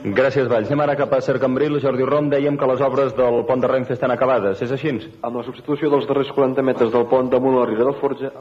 Connexió amb els estudis de SER Cambrils per informar de l'acabament de les obres al pont de RENFE de Cambrils
Informatiu